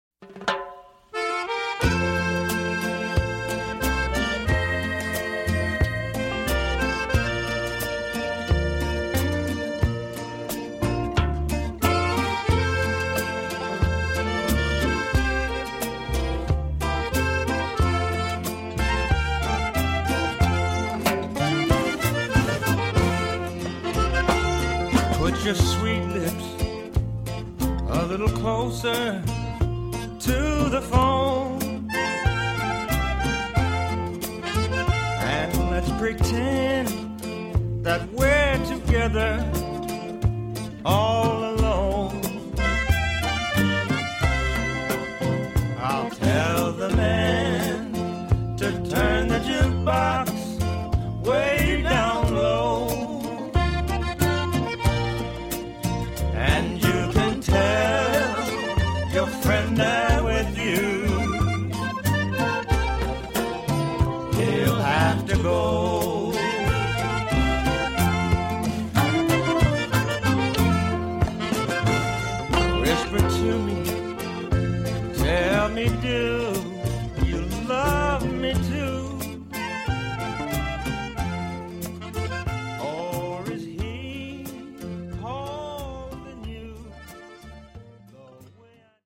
diatonic button accordion
in bolero rhythm